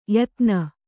♪ yatna